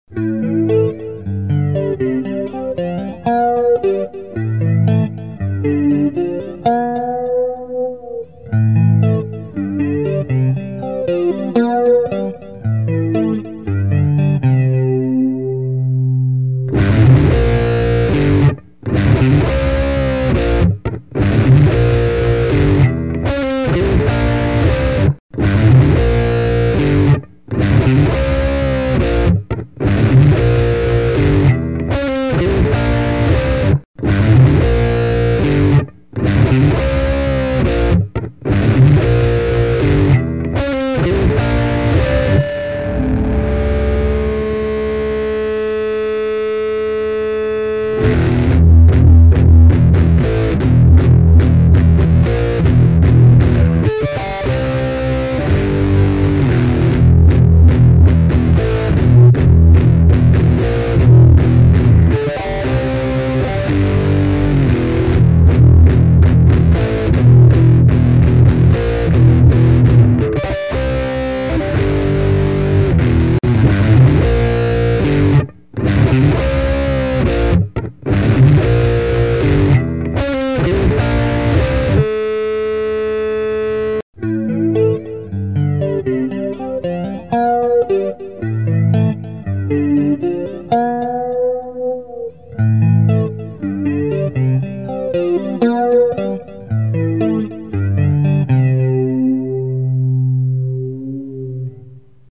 Below are just a few riffs that I recorded so I won't forget.
- Recorded direct line out with my Roland Cube 60 Amp modeler.